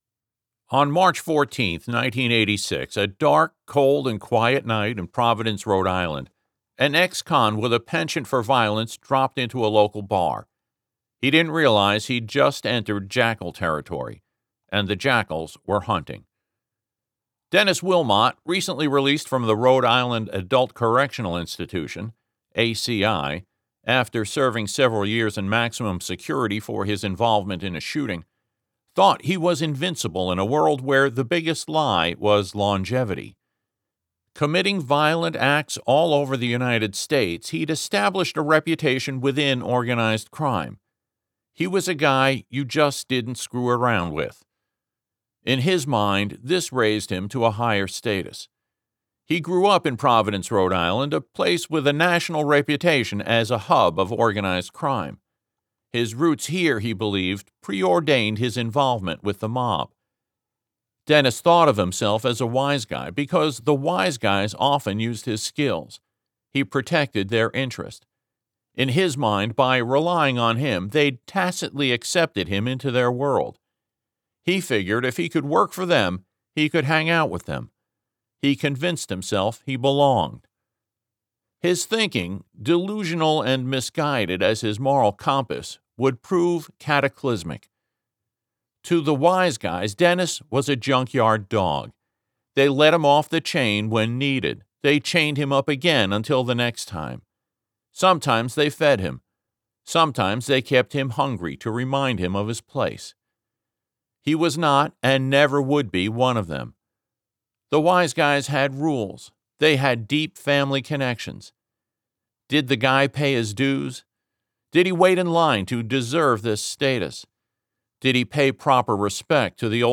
For your dining and dancing pleasure, some samples of my lovely, sometimes rough and growly, voice-